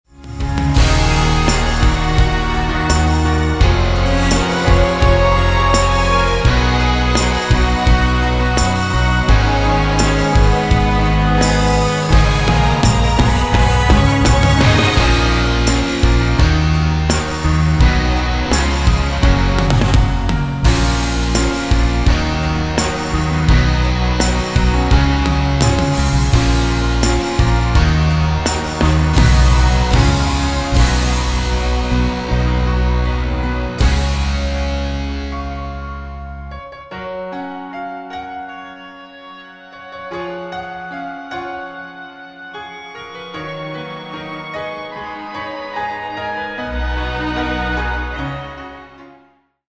Kategorie: Pop
Instrumental
backing track